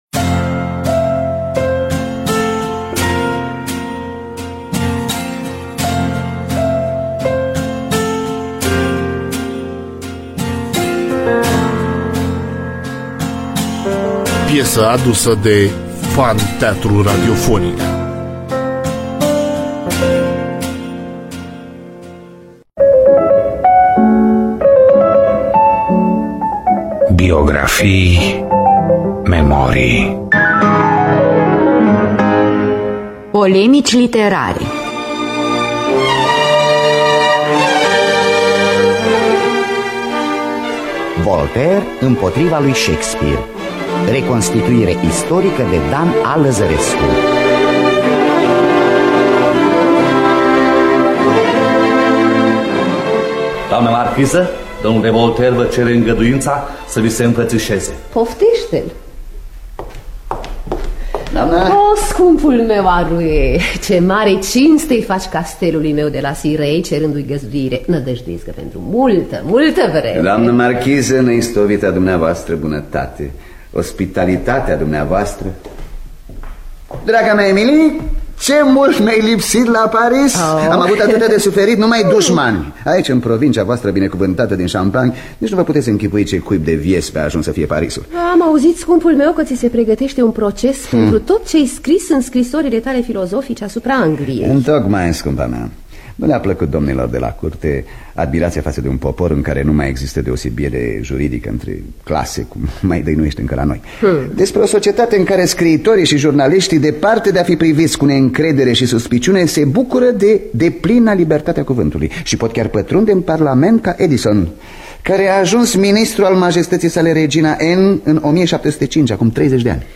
Biografii, Memorii: Voltaire Impotriva Lui Shakespeare (Polemici Literare) – Teatru Radiofonic Online